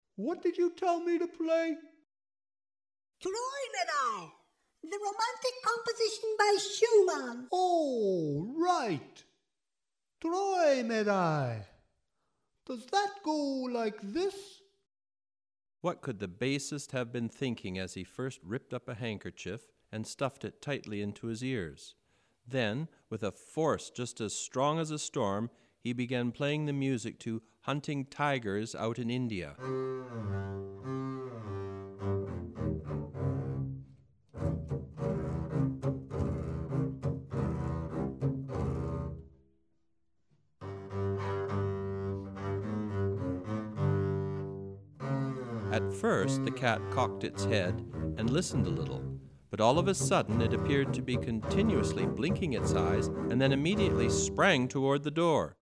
An audio book with music. Kenji Miyazawa's well-known and well-loved story, "Gauche The Cellist", comes to life once again in this delightful adaptation for the double bass.